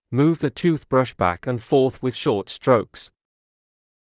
ﾑｰﾌﾞ ｻﾞ ﾄｩｰｽﾌﾞﾗｯｼ ﾊﾞｯｸ ｴﾝﾄﾞ ﾌｫｰｽ ｳｨｽﾞ ｼｮｰﾄ ｽﾄﾛｰｸｽ